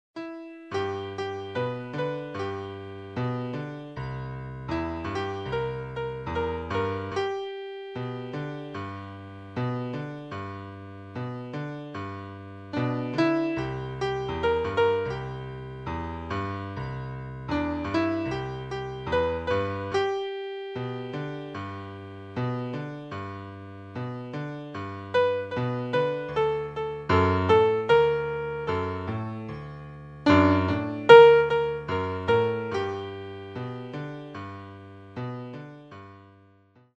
Style: New Orleans Piano